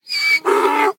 Minecraft Version Minecraft Version 1.21.5 Latest Release | Latest Snapshot 1.21.5 / assets / minecraft / sounds / mob / horse / donkey / angry2.ogg Compare With Compare With Latest Release | Latest Snapshot
angry2.ogg